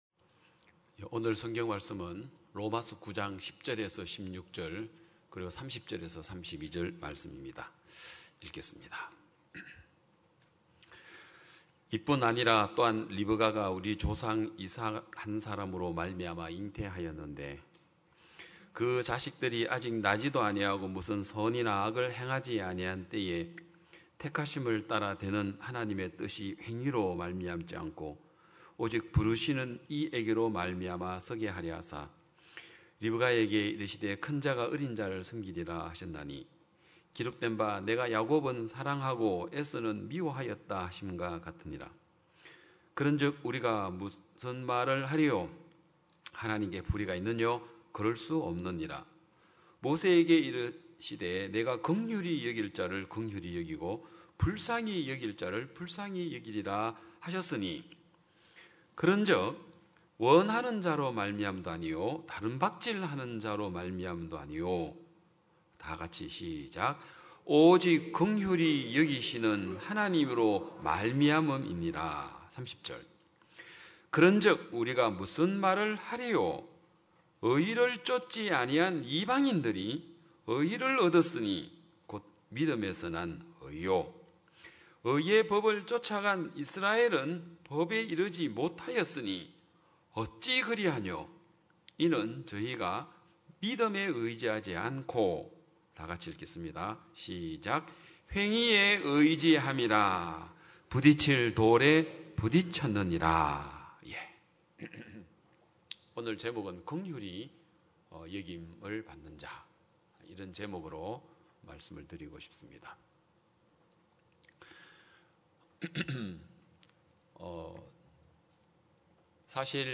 2022년 8월14일 기쁜소식양천교회 주일오전예배
성도들이 모두 교회에 모여 말씀을 듣는 주일 예배의 설교는, 한 주간 우리 마음을 채웠던 생각을 내려두고 하나님의 말씀으로 가득 채우는 시간입니다.